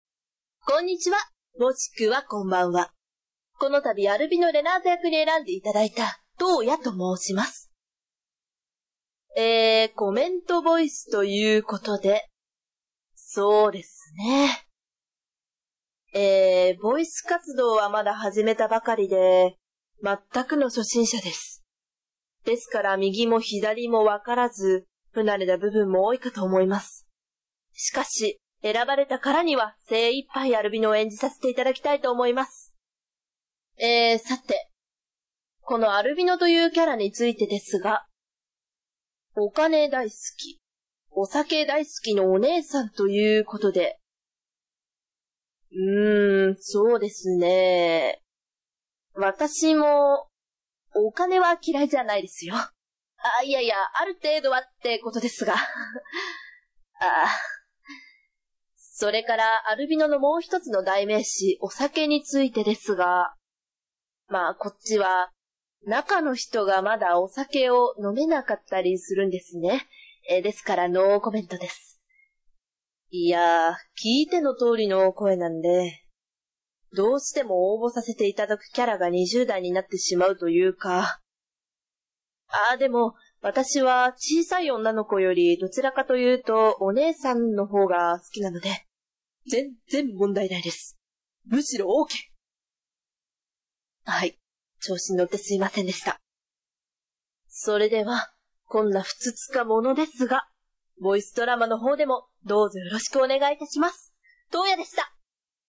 コメントボイス"